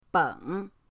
beng3.mp3